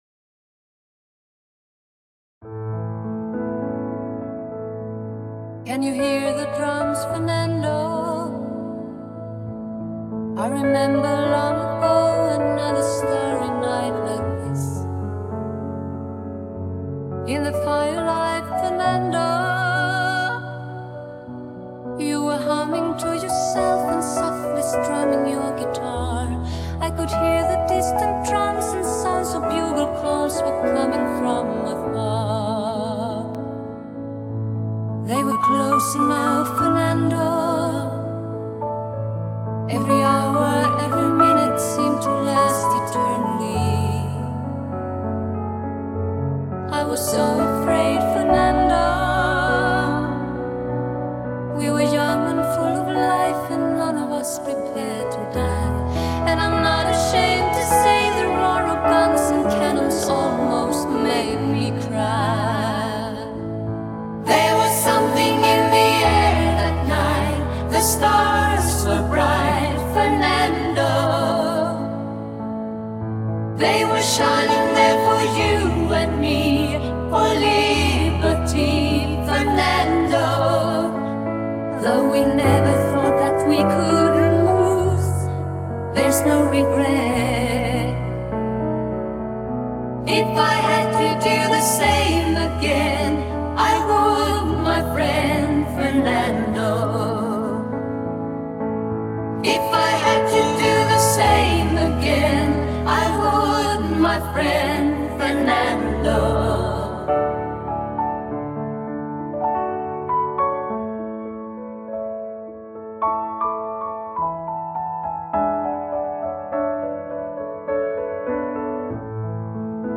And when the final notes fade, they don’t end – they linger.